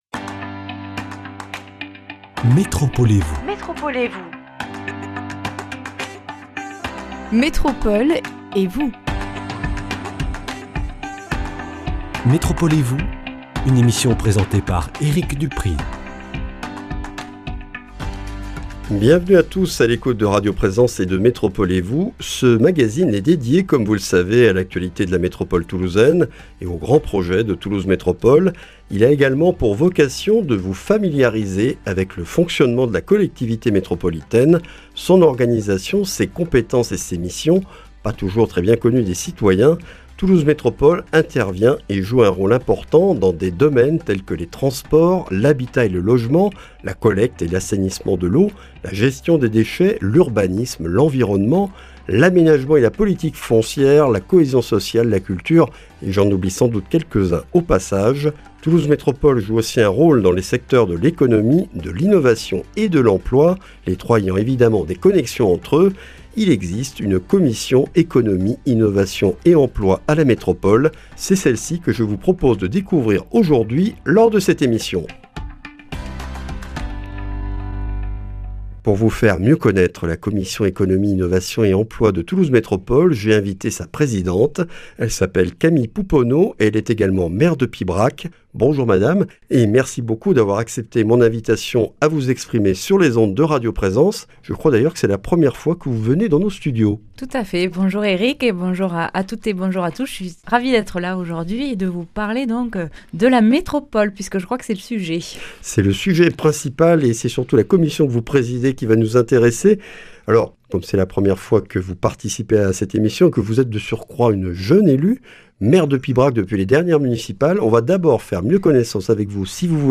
Camille Pouponneau est maire de Pibrac et présidente de la commission Économie, Innovation et Emploi de Toulouse Métropole. Elle nous explique le rôle et le fonctionnement de cette commission qui traite de sujets très transversaux au sein de la collectivité métropolitaine, et évoque les relations qu’elle entretient avec élus et acteurs locaux de l’économie, de l’innovation et de l’emploi.